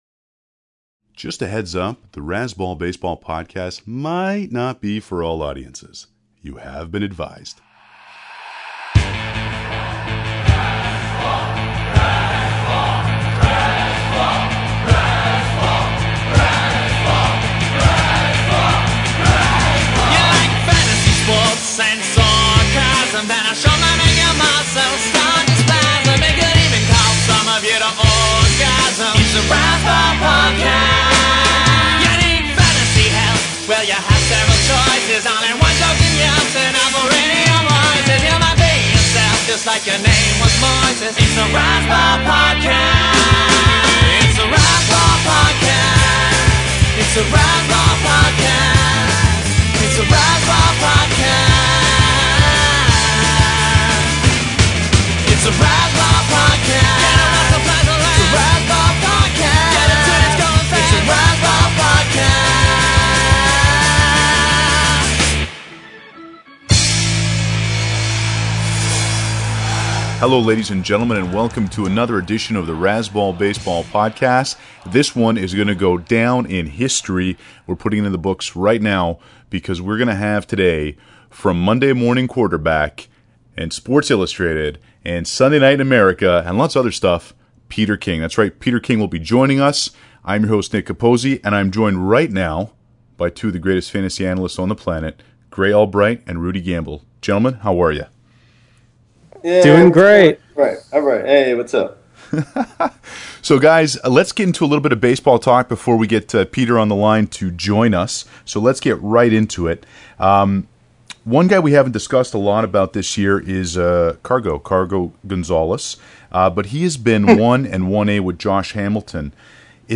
The great, incomparable, adjective Peter King came by Razzball HQ (virtually) and answered some hard-hitting questions.